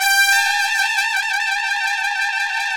Index of /90_sSampleCDs/Roland LCDP06 Brass Sections/BRS_Tpts FX menu/BRS_Tps FX menu